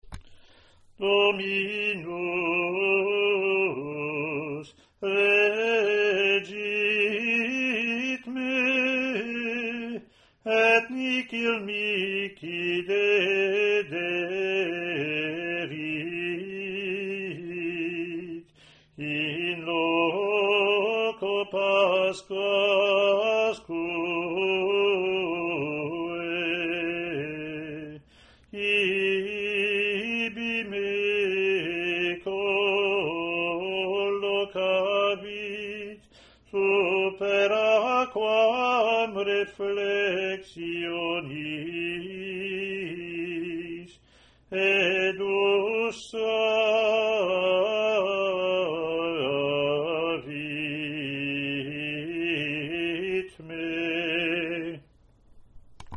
Year BC Latin antiphon + verse)